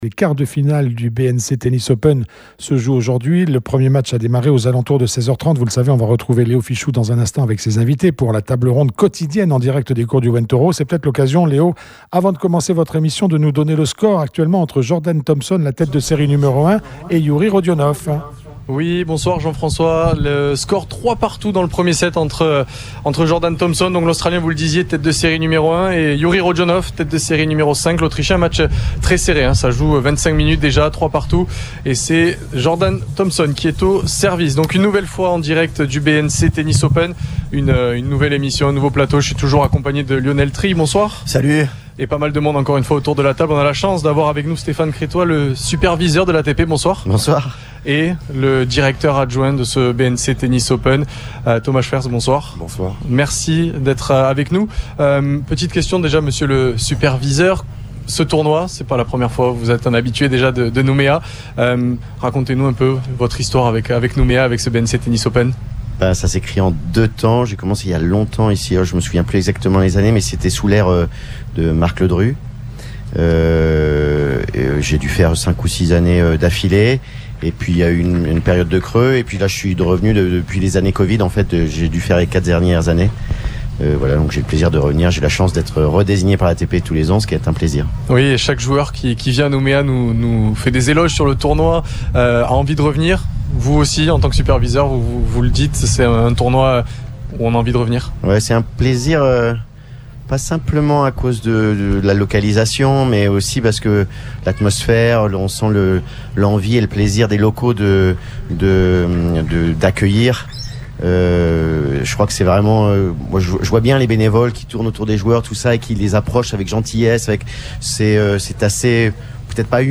Le BNC Tennis Open 2026 sur les courts du Ouen Toro.